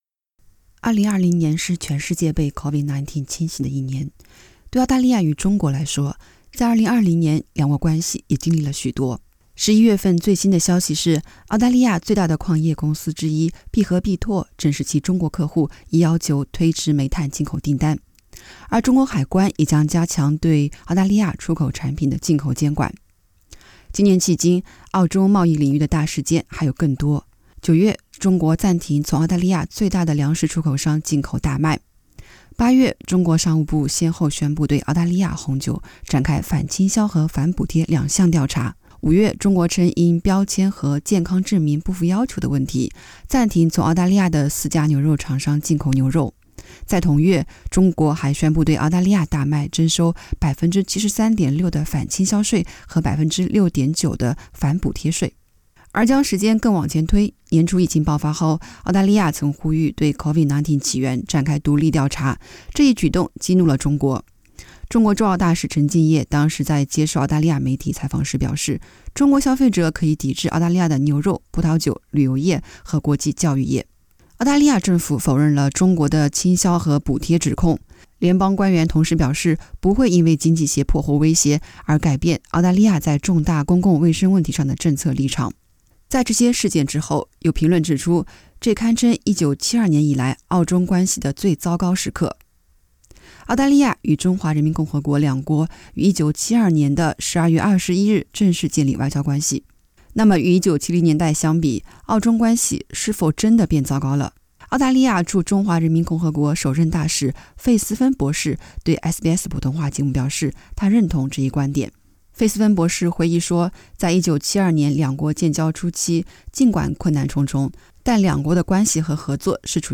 更多內容，可關注費思棻博士專訪之二。